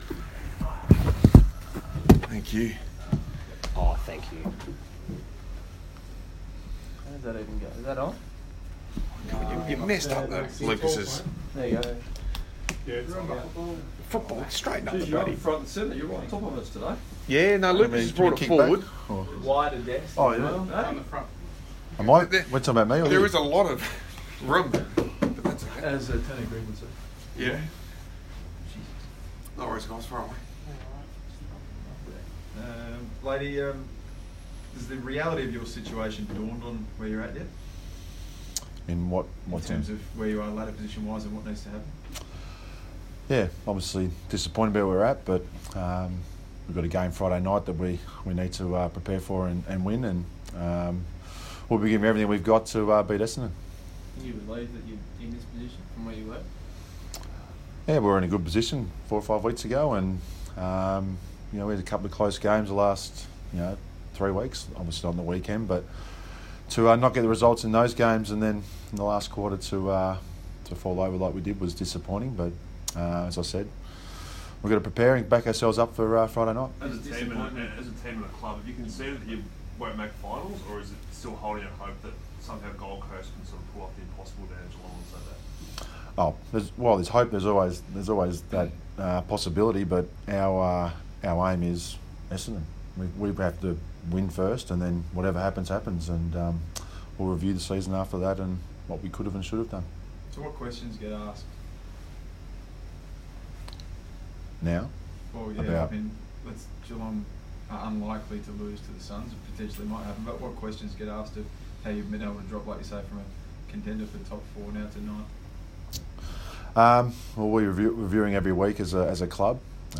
Brendon Lade press conference - Monday 20 August 2018